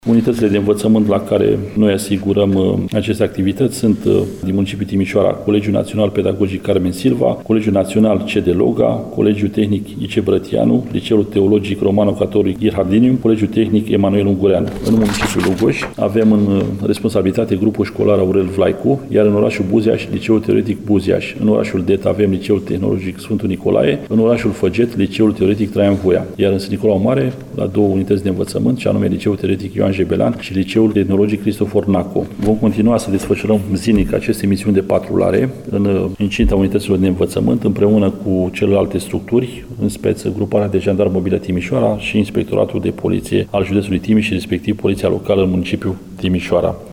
Comandantul Inspectoratului Județean de Jandarmerie Timiș, colonel Marian Toma, precizează că rețeaua școlară beneficiază de supraveghere nu doar din partea jandarmilor, cât și a altor structuri ale Ministerului Afacerilor Interne și de Poliția Locală.